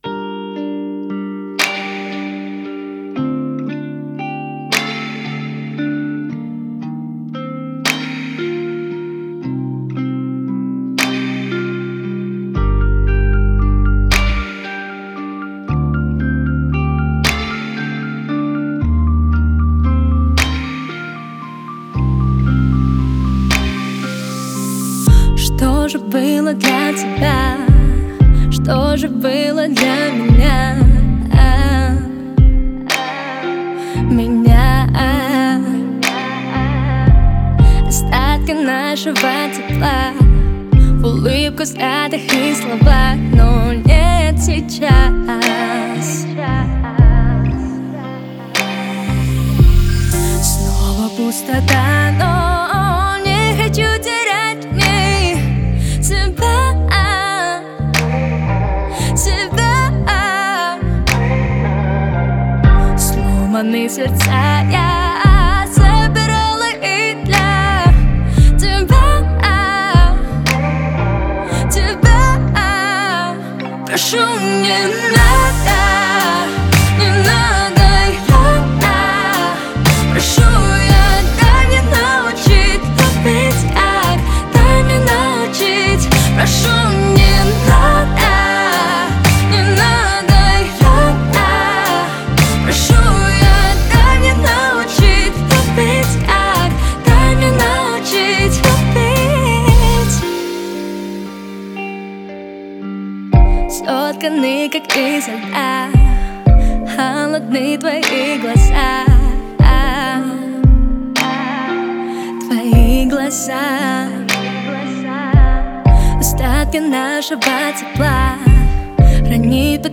это проникновенная композиция в жанре поп
Звучание отличается мелодичностью и душевностью